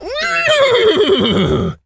pgs/Assets/Audio/Animal_Impersonations/horse_neigh_04.wav at master
horse_neigh_04.wav